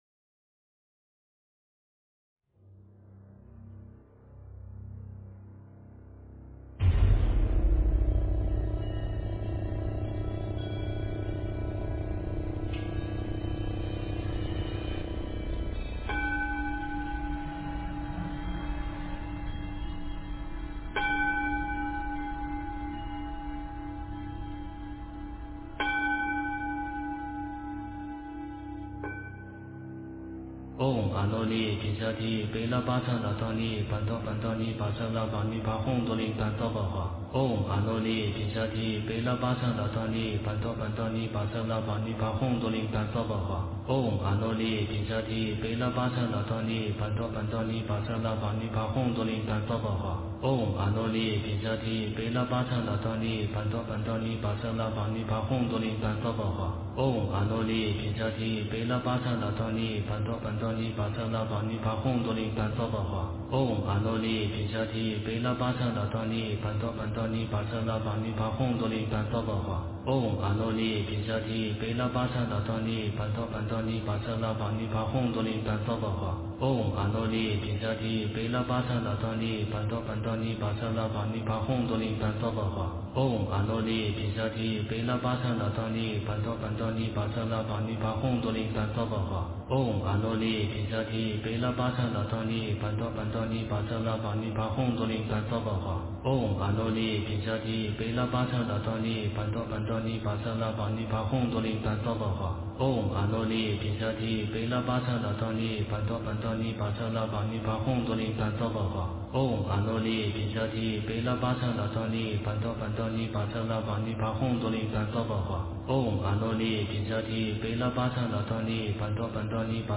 诵经
佛音 诵经 佛教音乐 返回列表 上一篇： 长寿佛经 下一篇： 佛说决定毗尼经 相关文章 地藏经15--梦参法师 地藏经15--梦参法师...